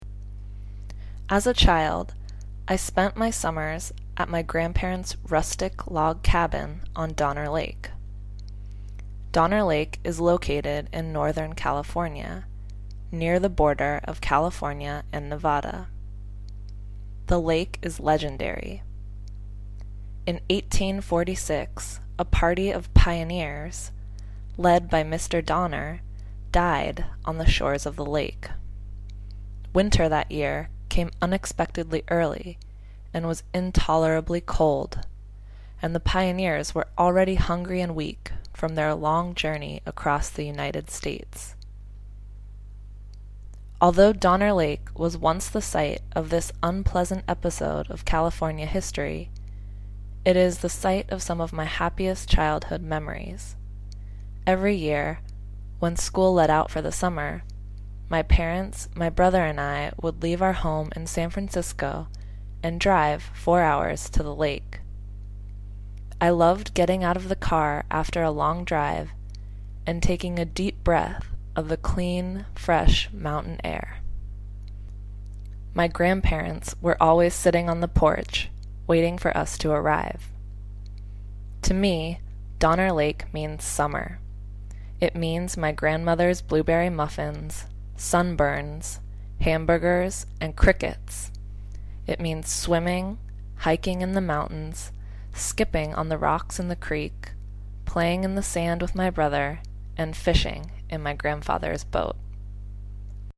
Accent
Américain